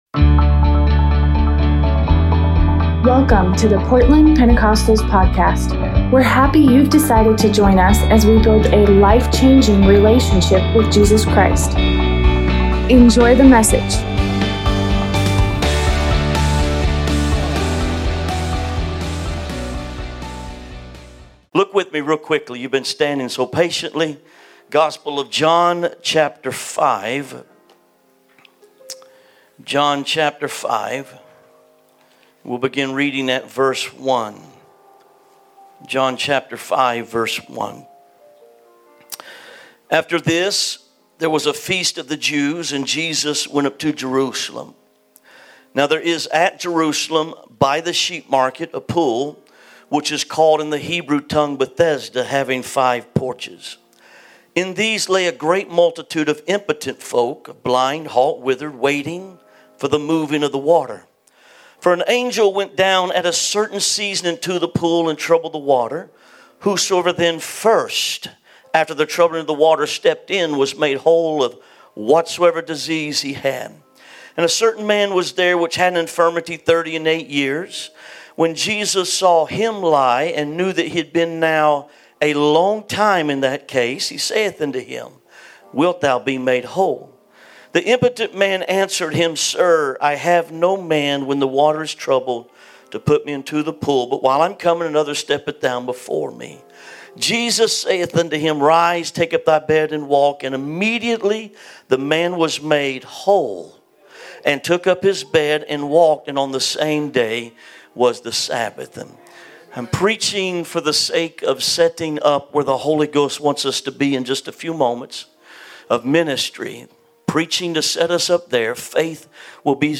Tuesday night sermon